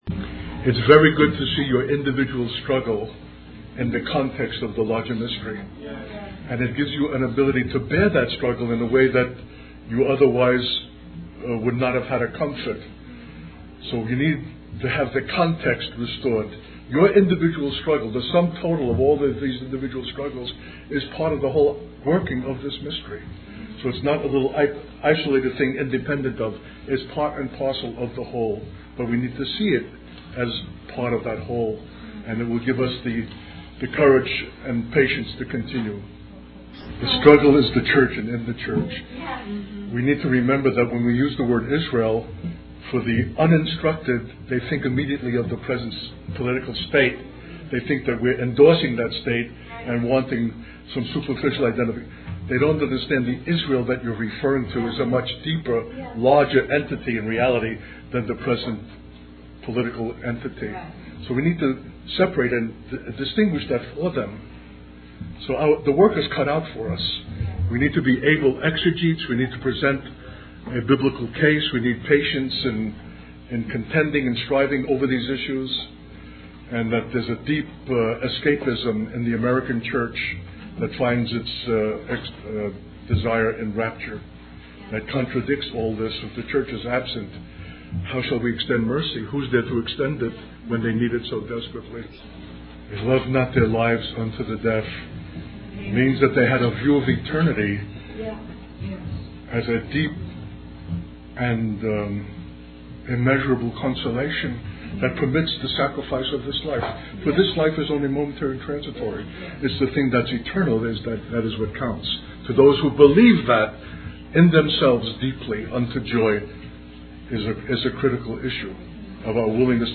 In this sermon, the speaker emphasizes the importance of showing mercy to others, especially in times of persecution. He highlights that this mercy should be given sacrificially, both physically and emotionally. The speaker also discusses the concept of the mystery of God's plan, which is fulfilled through the glory of God.